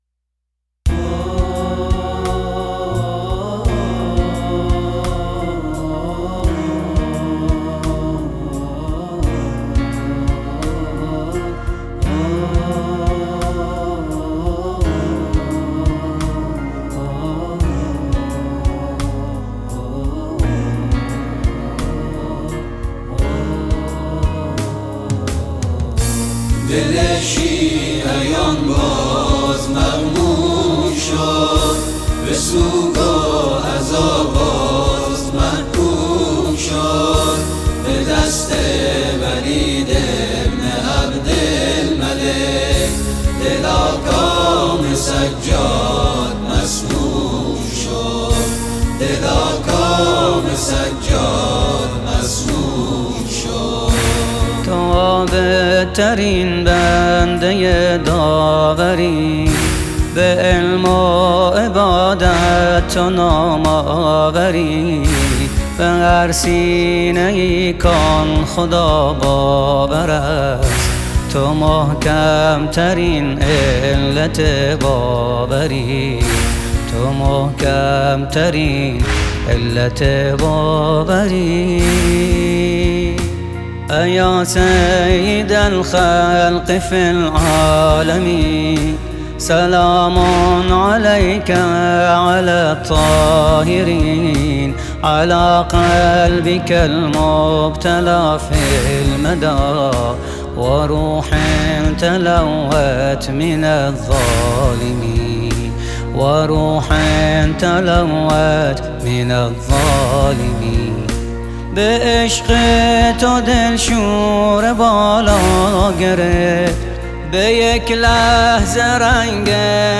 گروه موسیقی آئینی